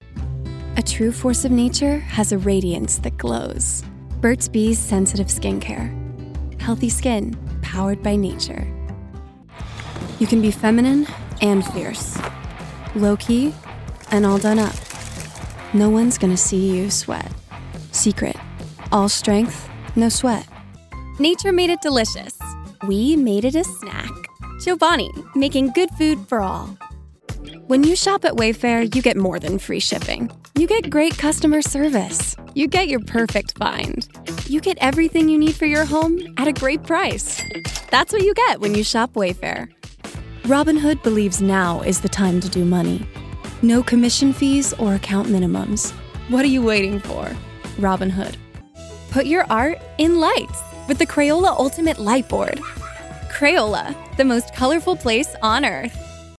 Voiceover : Commercial : Women
Commercial Demo